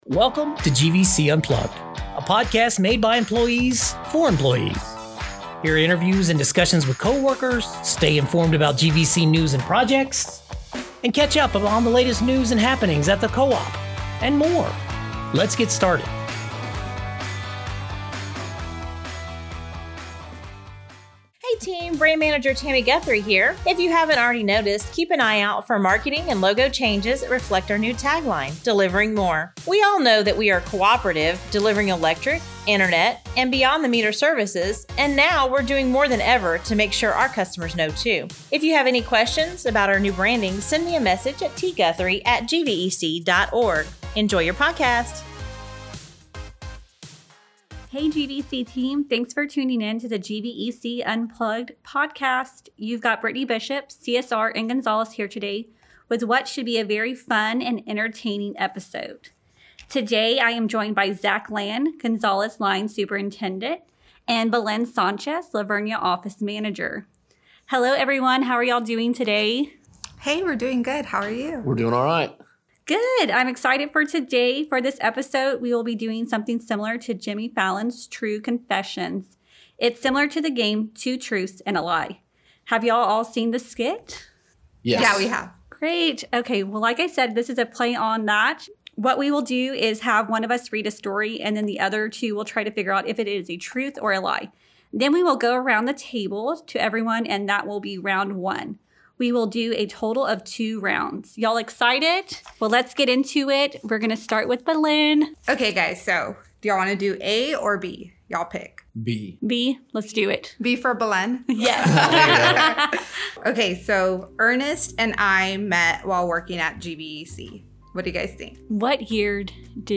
The latest episode of the GVEC Unplugged Podcast features three GVEC story-tellers who take turns confessing a random fact before interrogating each other to determine...is it the truth or a lie?